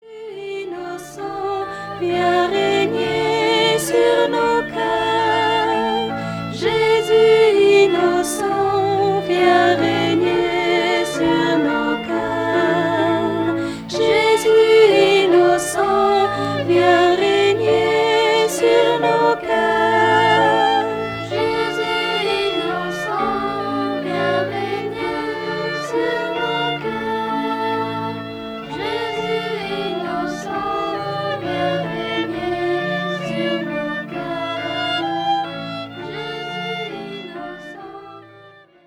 Interprétés par un choeur d'enfants